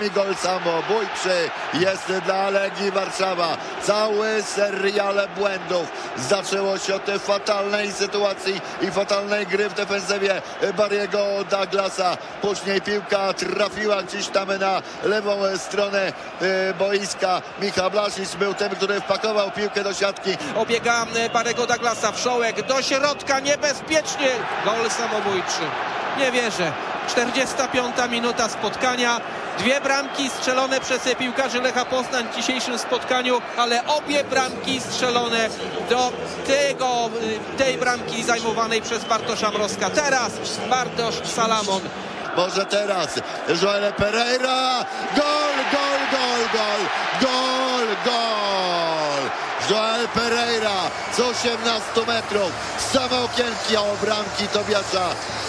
uuori392pjy45ub_gole-lech-legia.mp3